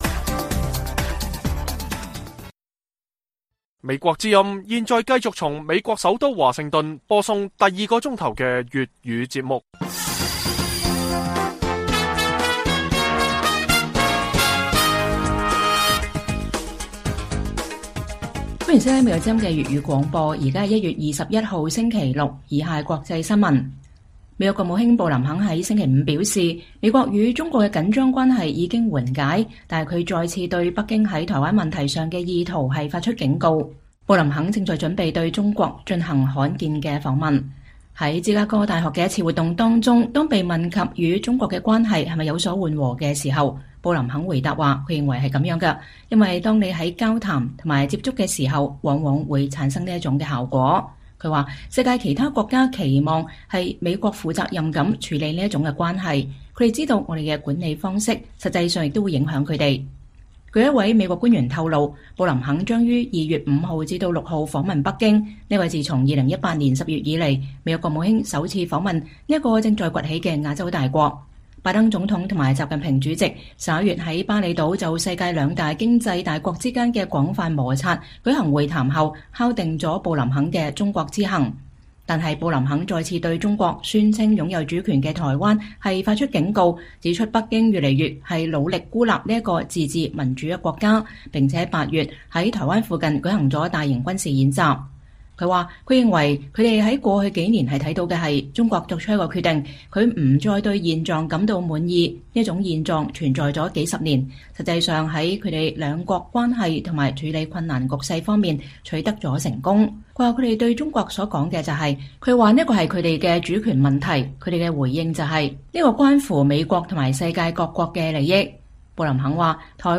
粵語新聞 晚上10-11點：布林肯認為美國與中國的緊張關係有所緩解，但台灣問題存在風險